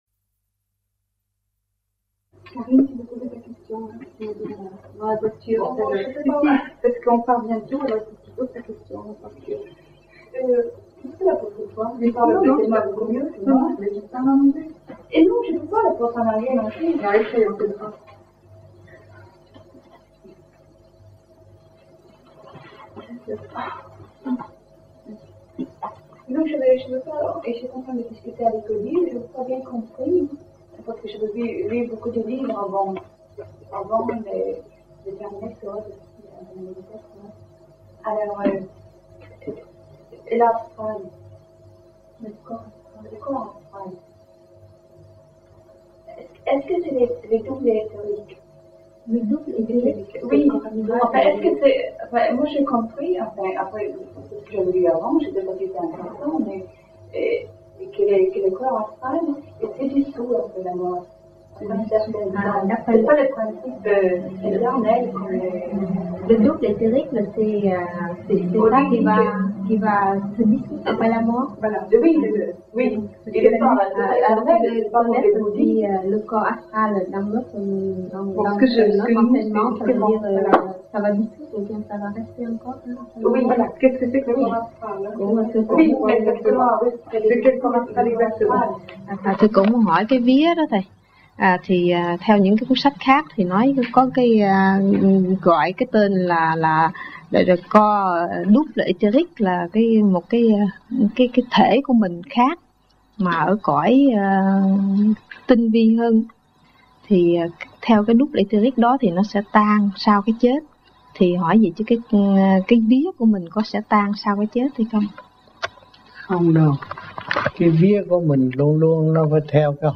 1980 Đàm Đạo